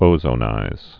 (ōzō-nīz, -zə-)